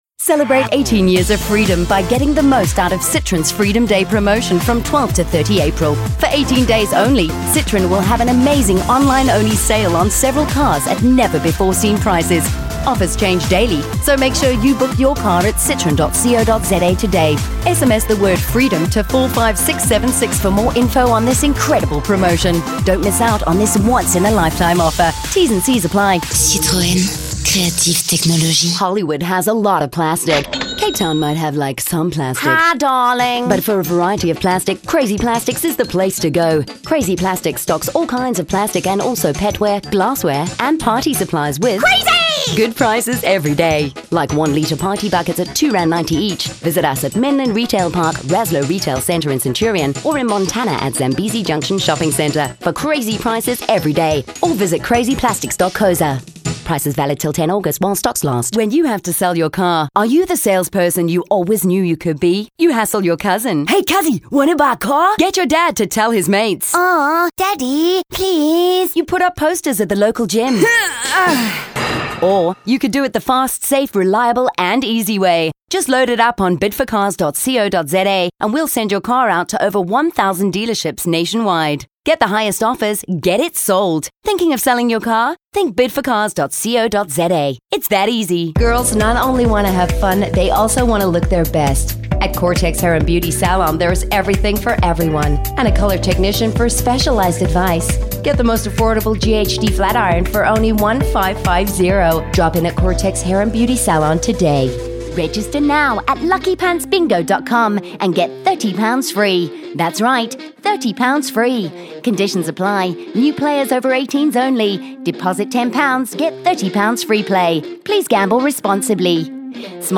Female
40s/50s, 50+
English Neutral, South African